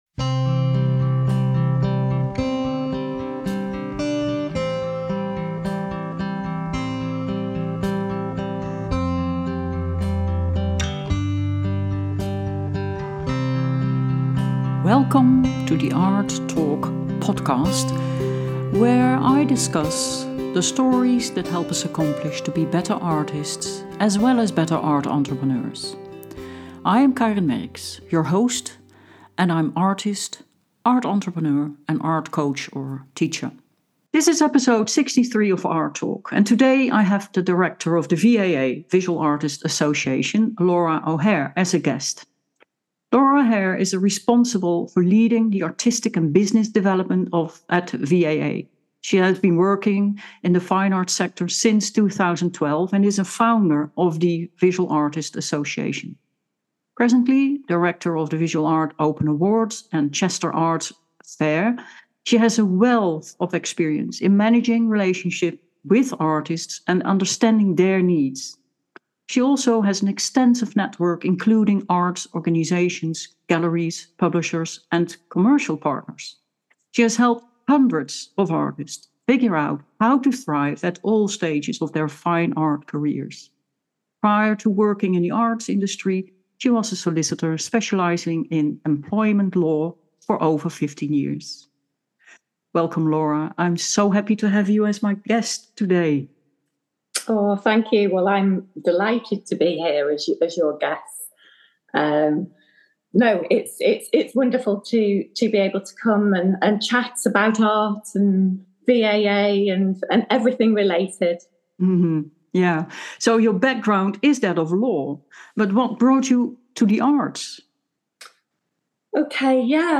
In episode 63 of Art Talk, I have a conversation